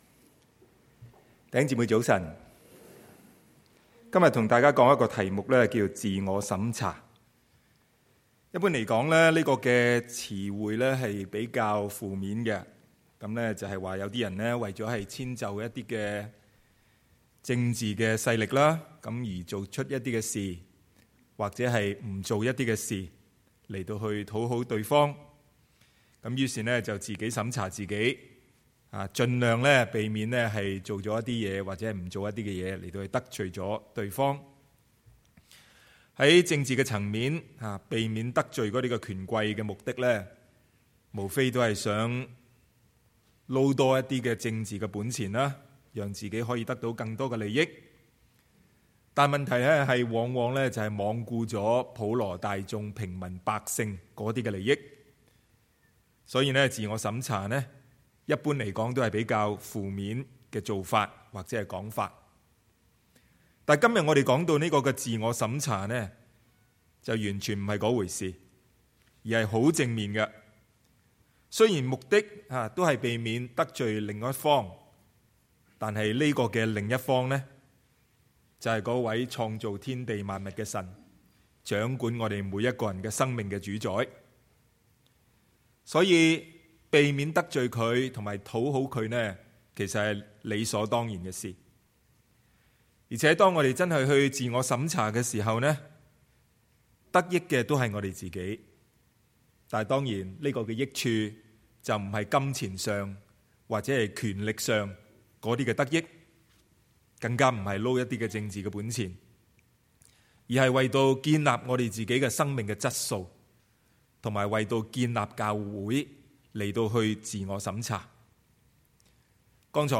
Sermons | Fraser Lands Church 菲沙崙教會